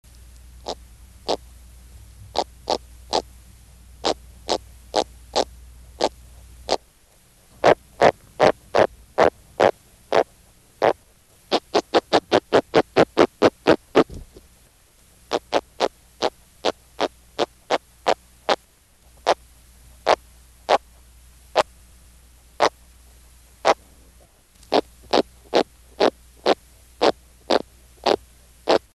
Sounds Made by Caranx hippos
Sound produced yes, active sound production
Type of sound produced croaks
Sound production organ teeth & swim bladder
Sound mechanism stridulation of well-toothed mouth reinforced by large swim bladder
Behavioural context spontaneous, sustained croaking during capture in net, sounds easily stimulated with mild manual stimulation (continued production even out of water)